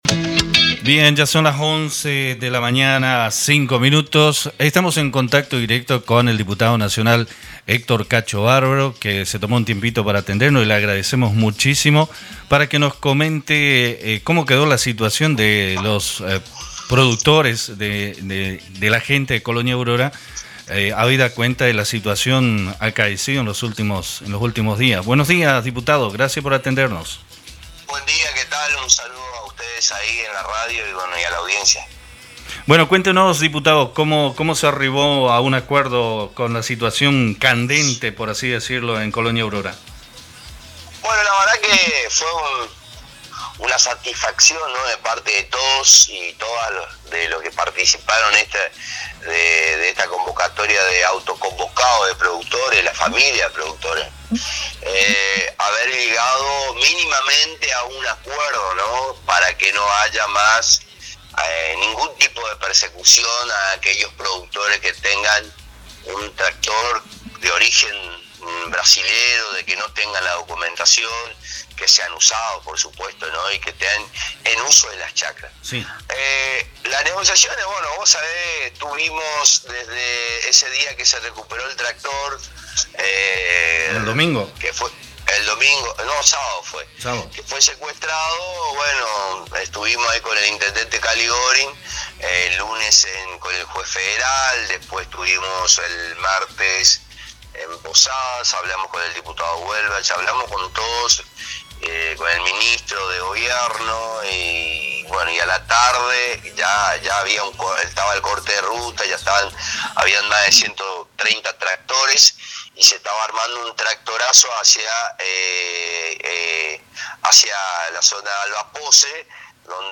En dialogo con Radio la Cueva el Diputado Nacional Hector «Cacho» Barbaro manifestó que luego de una jornada de mucho dialogo y mediación se logró destrabar el conflicto donde se llegó hasta la Ministra de Seguridad de la Nación Sabina Frederic a fin de poner un impase en esta situación para que se